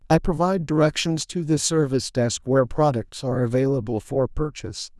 TTS_audio / PromptTTS++ /sample1 /Template2 /Condition /Customer /Emotion /surprised /flabbergasted.wav
flabbergasted.wav